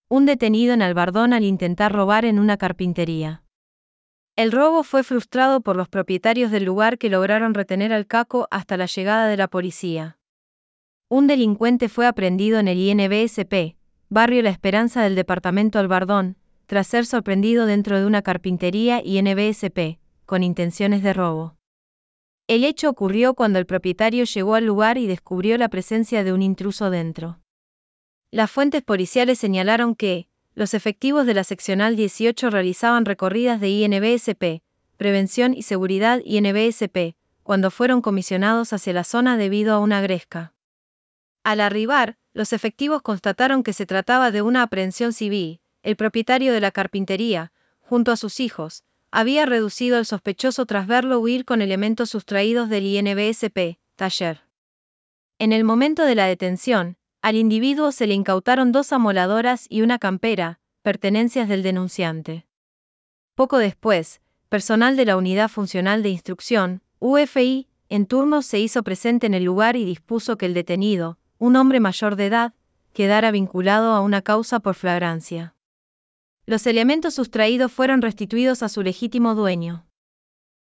Text_to_Speech-9.wav